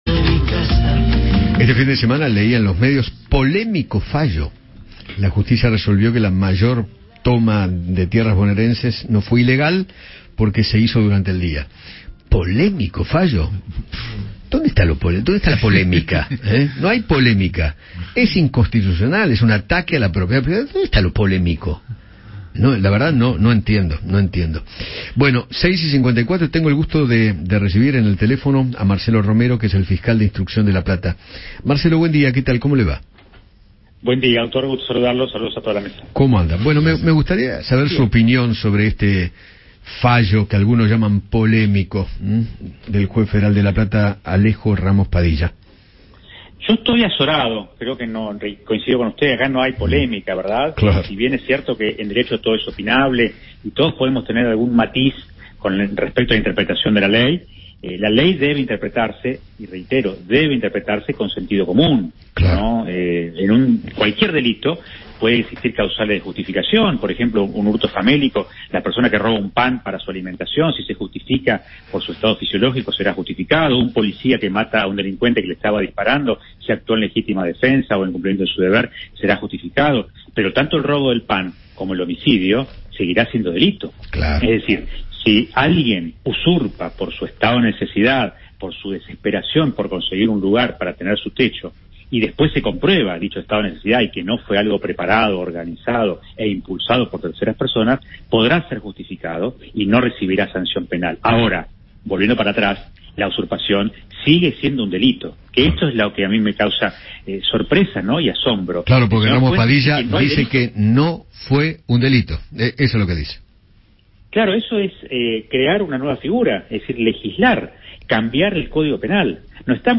Marcelo Romero, fiscal de Instrucción de La Plata, habló con Eduardo Feinmann sobre el fallo del juez federal Alejo Ramos Padilla, quien determinó que la toma de tierras en el ex predio nacional del Club Planeadores de la localidad de Los Hornos no fue un delito.